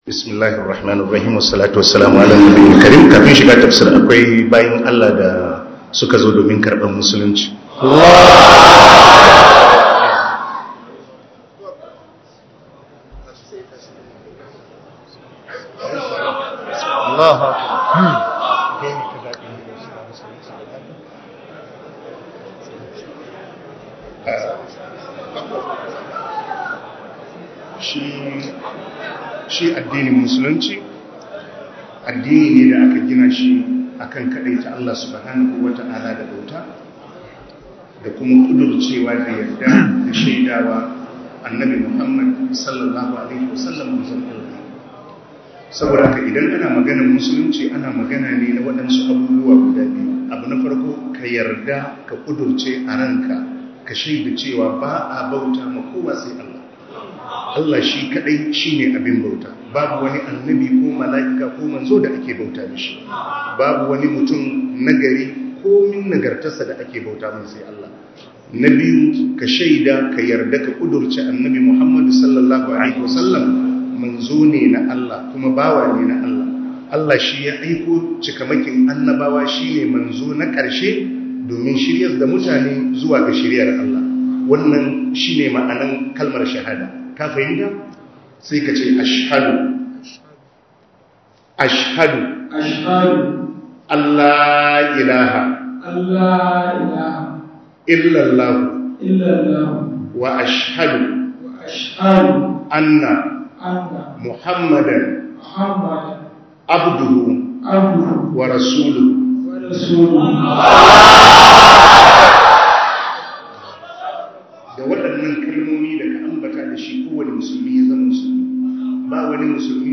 Audio lecture